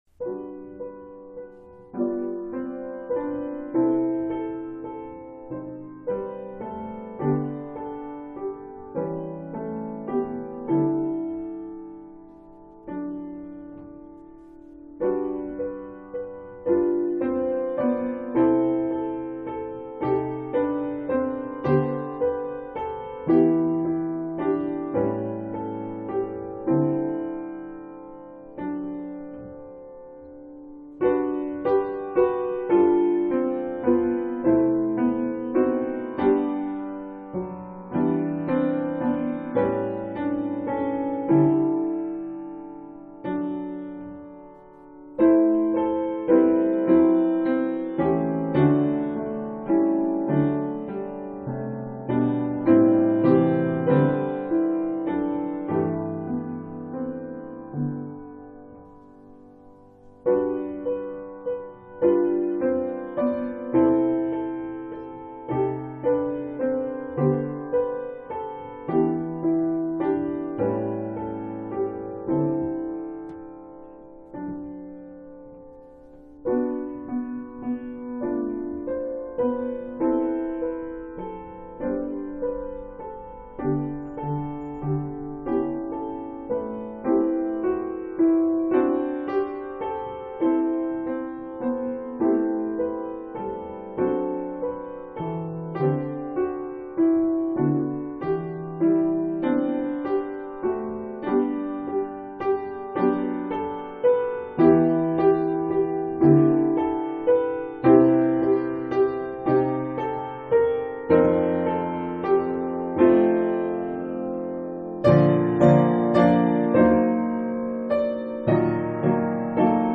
Jesus, The Very Thought of Thee — Piano — pdf